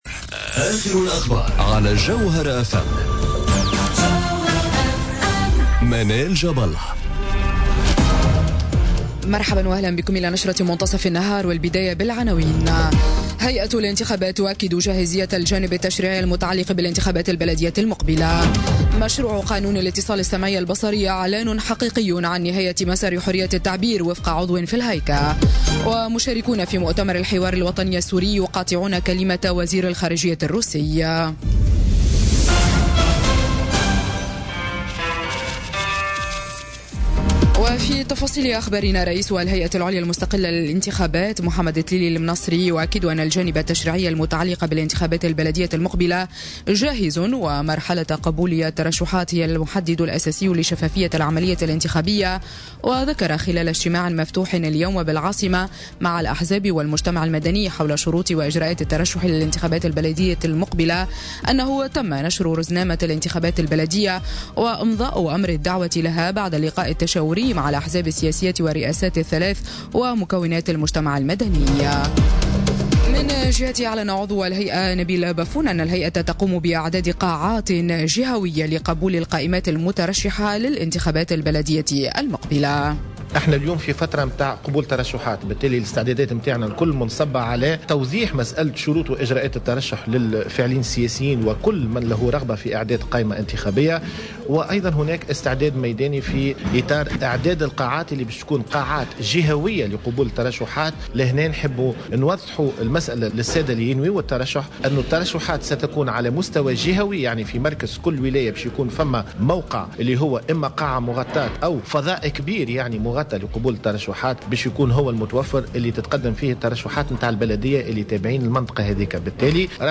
نشرة أخبار منتصف النهار ليوم الثلاثاء 30 جانفي 2018